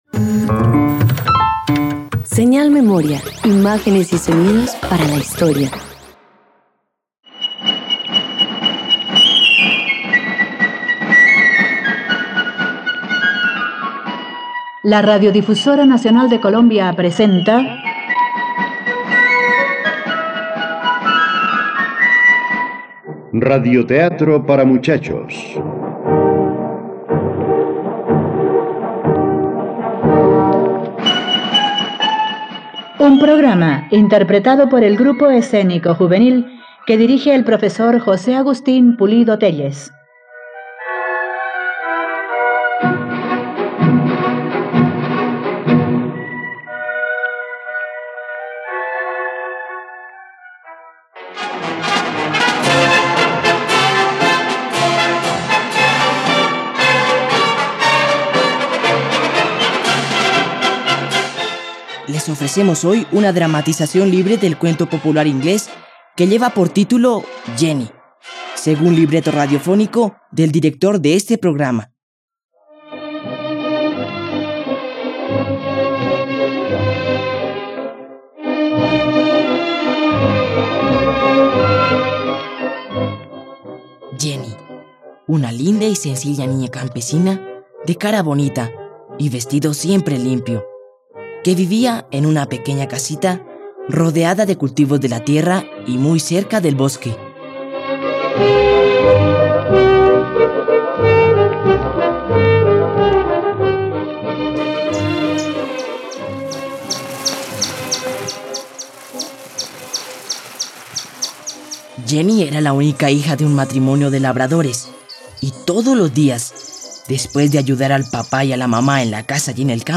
Jenny - Radioteatro dominical | RTVCPlay